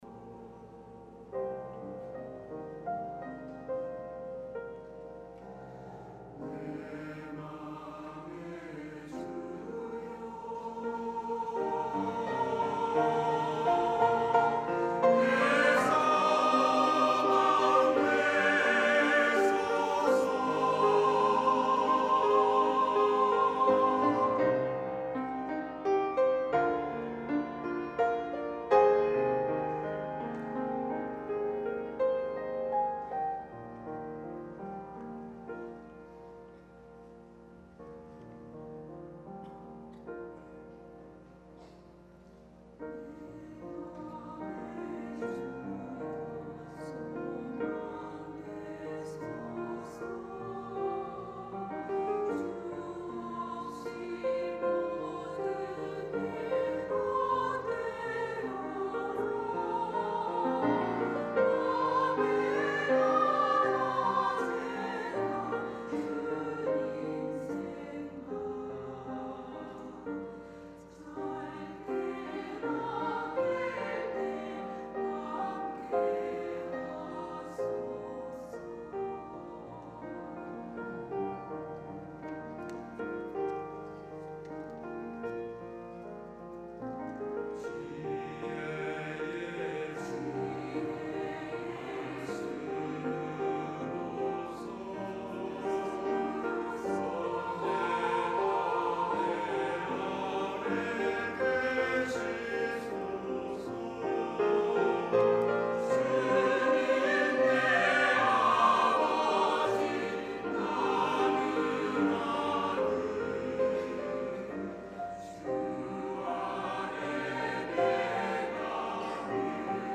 찬양 :: 141005 내 맘의 주여
" 내 맘의 주여 "- 시온 찬양대